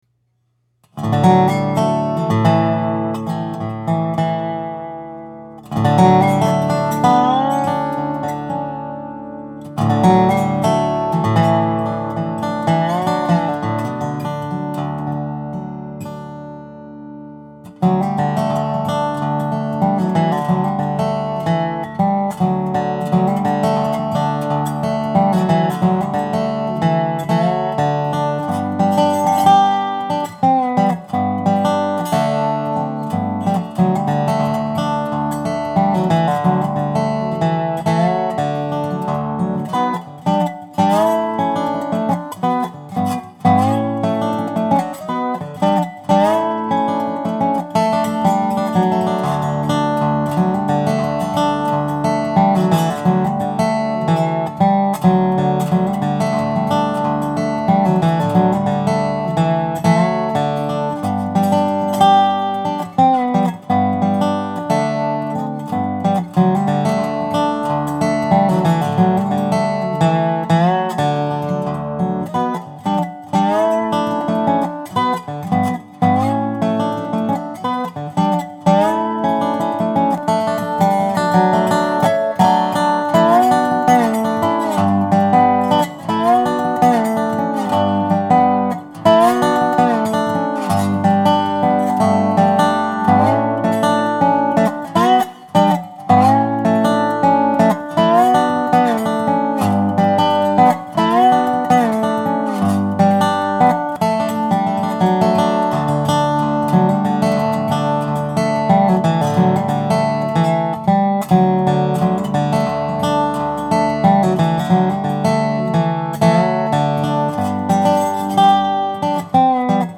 Dobro